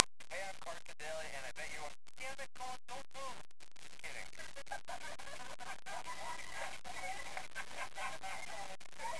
And this is Carson jokingly yelling at some kid.